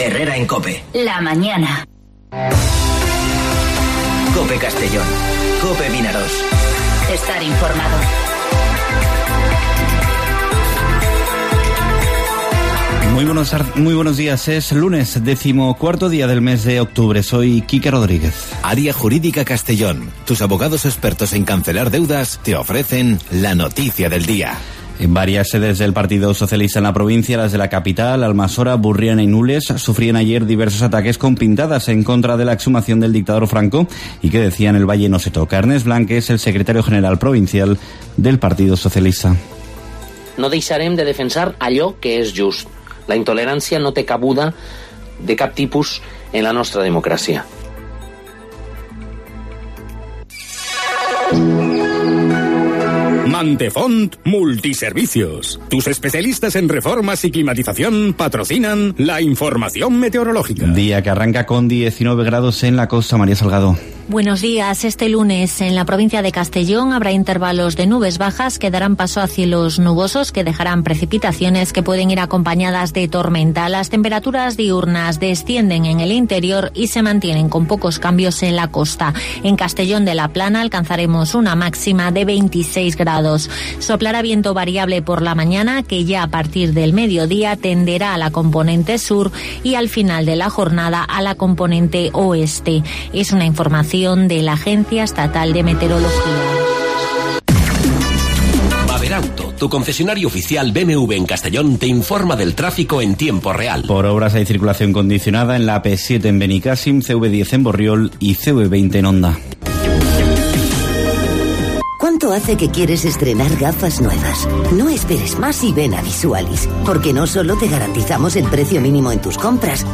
Informativo Herrera en COPE Castellón (14/10/2019)